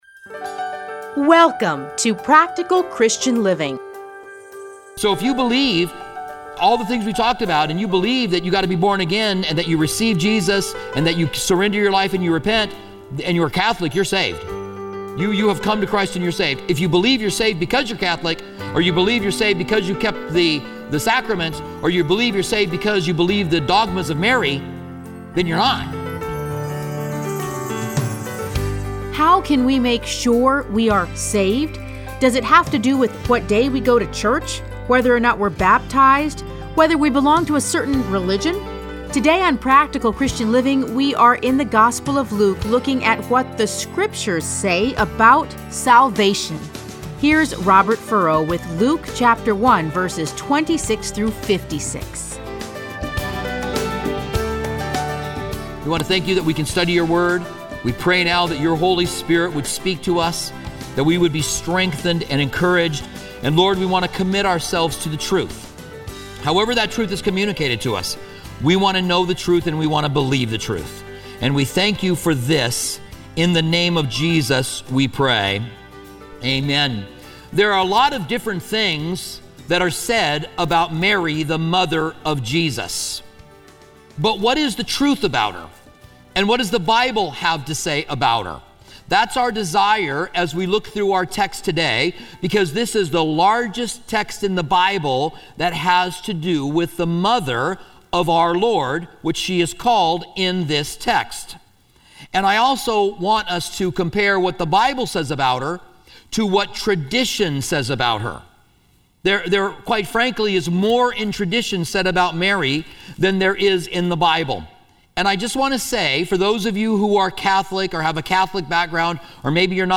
Listen to a teaching from Luke 1:26-56.